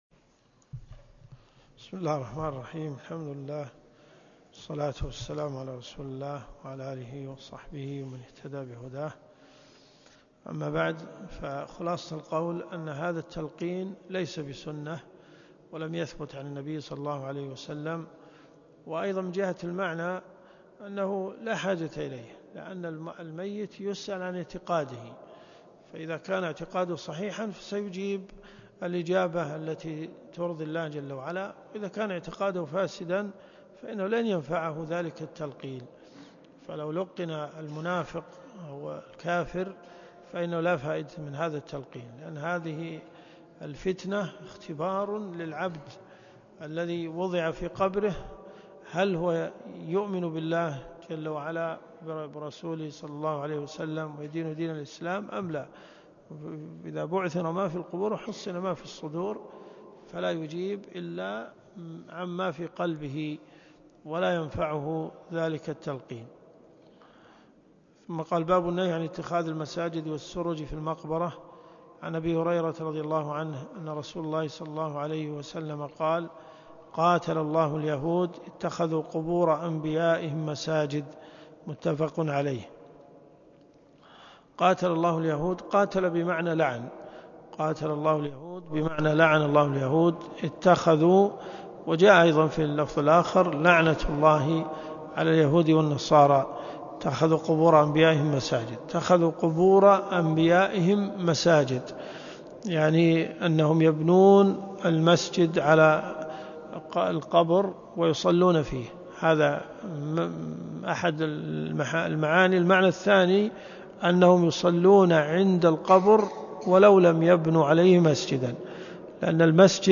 الدروس الشرعية
الرياض حي أم الحمام . جامع الملك خالد